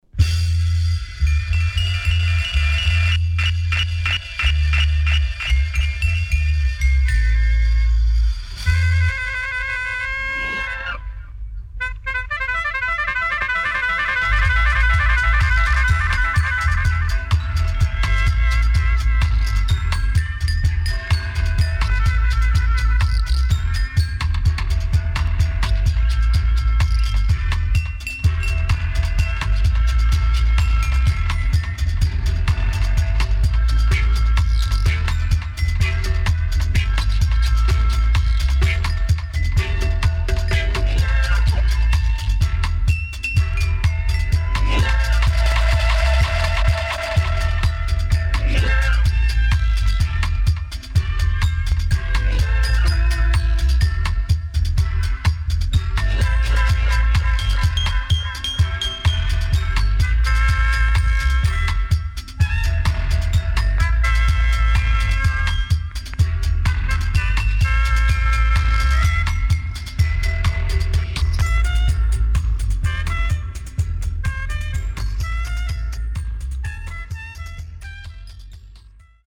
KILLER & DEEP STEPPER
【10inch】